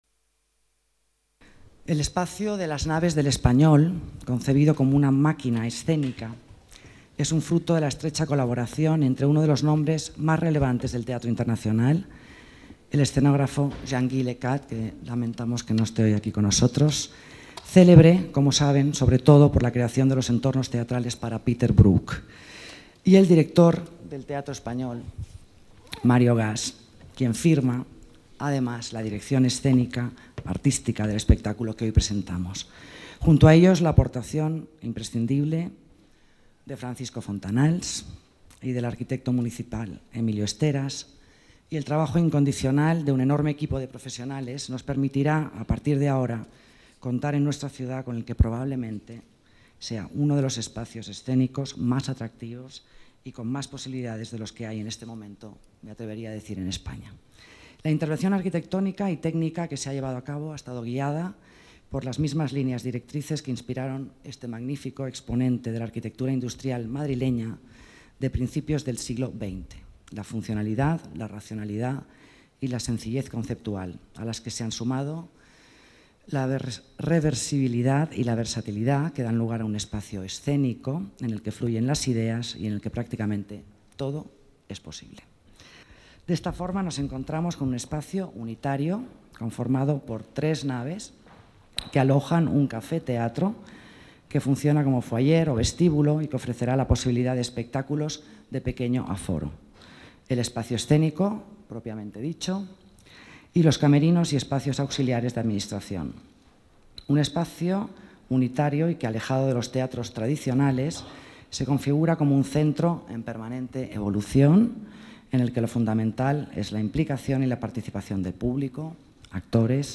Nueva ventana:Declaraciones de Alicia Moreno resaltando el moderno espacio escénico que conforman las Naves del Español en Matadero Madrid